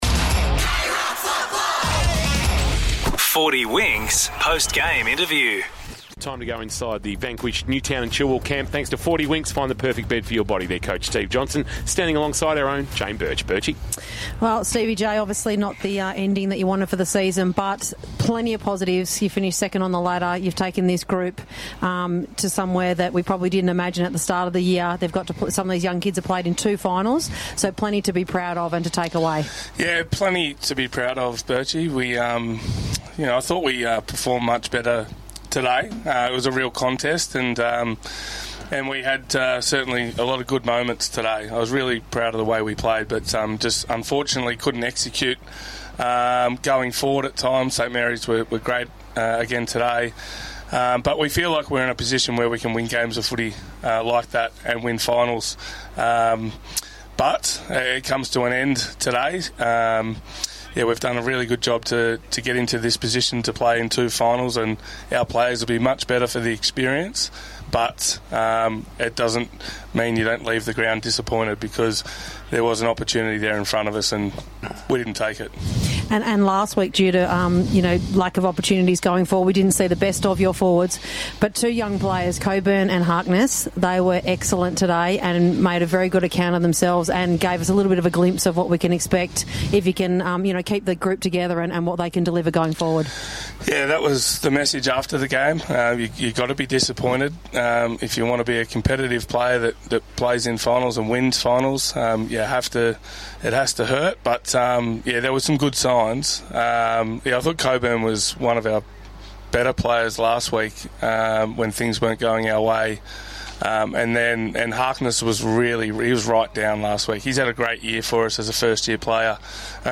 2025 - GFNL - First Semi-Final - Newtown & Chilwell vs. St Mary's - Post-match interview